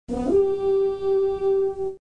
دانلود آهنگ شیپور جنگ 3 از افکت صوتی اشیاء
جلوه های صوتی